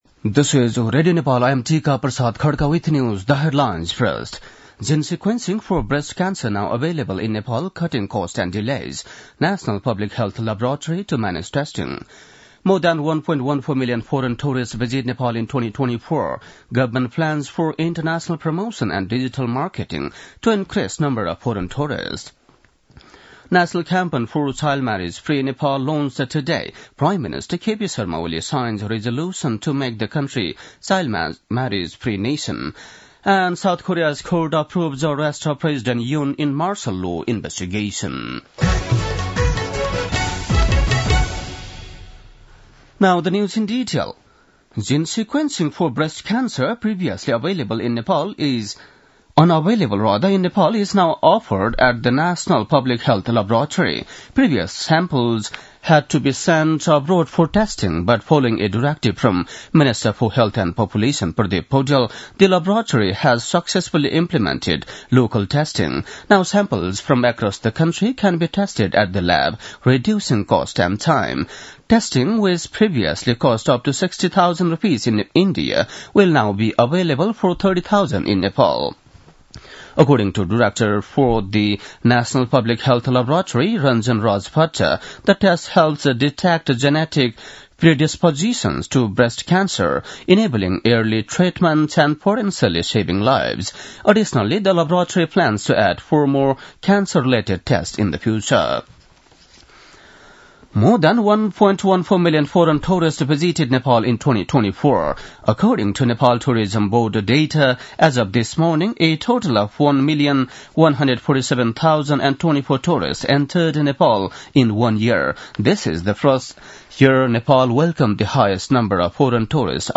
बेलुकी ८ बजेको अङ्ग्रेजी समाचार : १७ पुष , २०८१